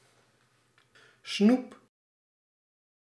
Ääntäminen
IPA : [ˈkæn.di]